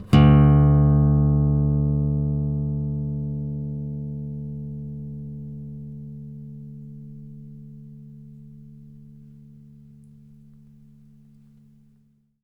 bass-17.wav